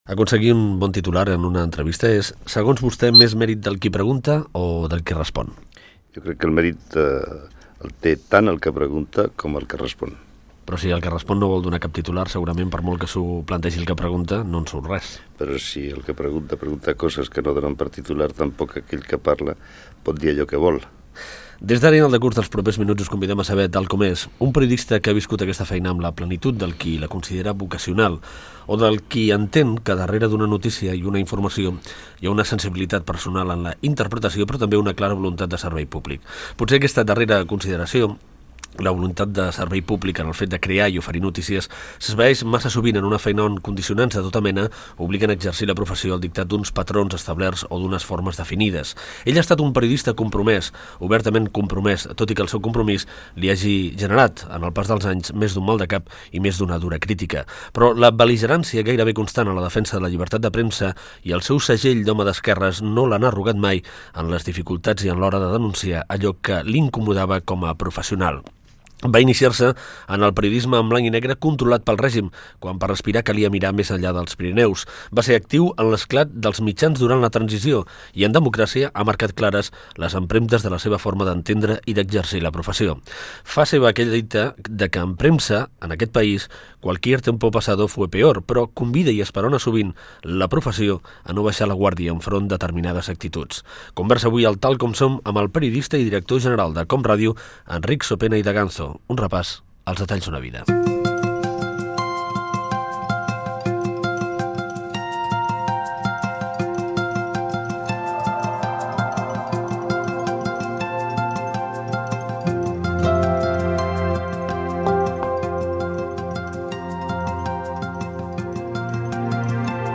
Fragment d'una entrevista amb detalls de la vida del periodista Enric Sopena (aleshores director general de COM Ràdio.).
Entreteniment